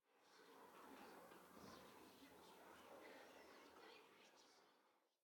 whisper4.ogg